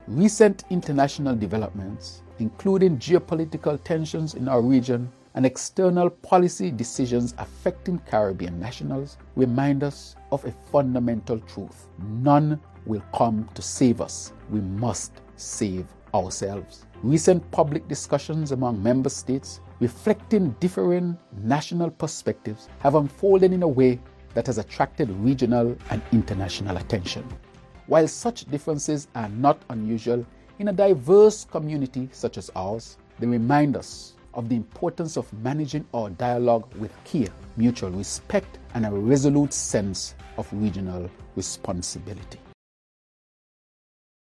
Pm Drew also commented: